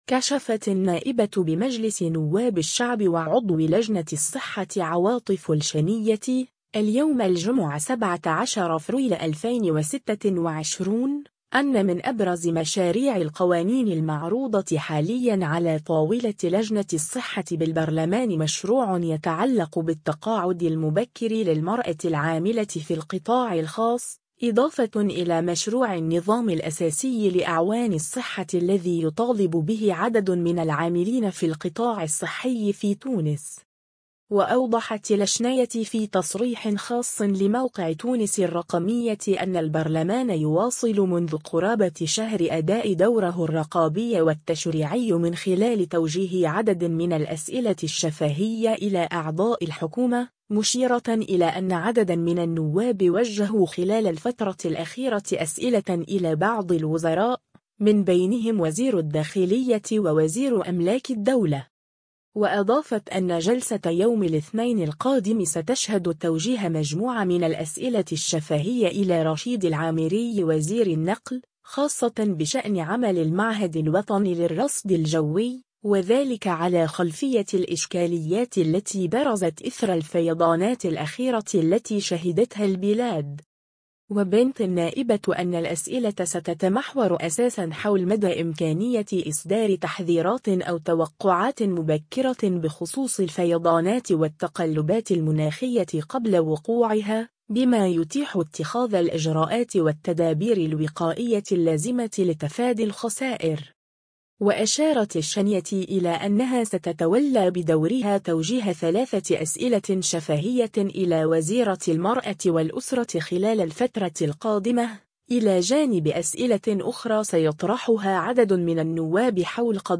وأوضحت الشنيتي في تصريح خاص لموقع “تونس الرقمية” أن البرلمان يواصل منذ قرابة شهر أداء دوره الرقابي والتشريعي من خلال توجيه عدد من الأسئلة الشفاهية إلى أعضاء الحكومة، مشيرة إلى أن عددا من النواب وجهوا خلال الفترة الأخيرة أسئلة إلى بعض الوزراء، من بينهم وزير الداخلية ووزير أملاك الدولة.